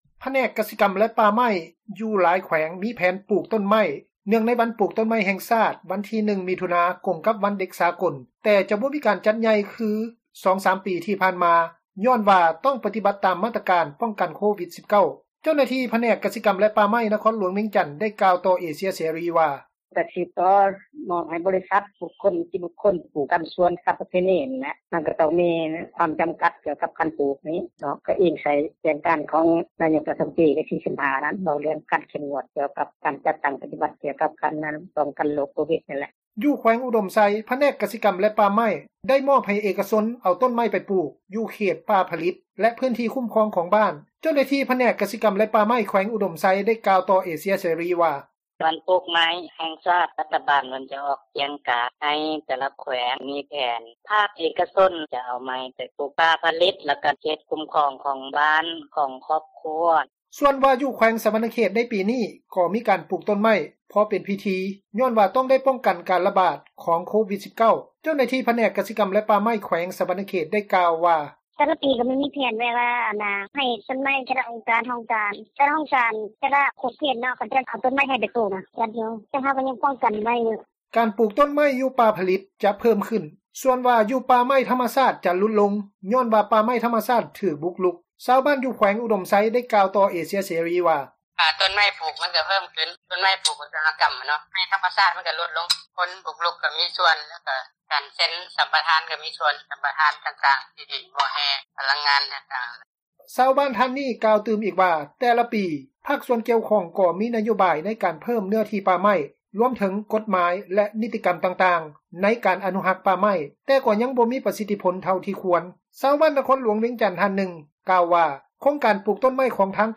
ວັນປູກຕົ້ນໄມ້ຢູ່ລາວ ຫຼາຍແຂວງ ຕຽມລົງມື — ຂ່າວລາວ ວິທຍຸເອເຊັຽເສຣີ ພາສາລາວ